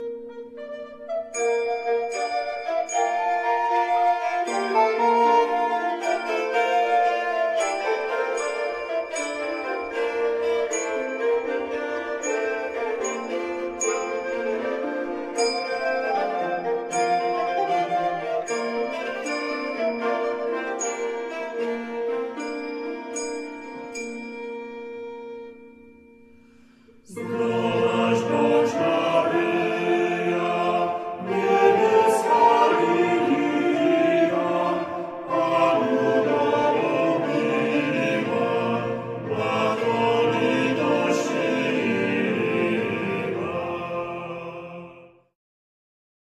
Współczesne prawykonania średniowiecznych utworów z Wrocławia, Cieszyna, Środy Śląskiej, Głogowa, Brzegu, Henrykowa, Żagania, kompozytorów anonimowych, Nicolausa Menczelliniego, hymny i sekwencja o św.
kontratenor, lutnia
fidel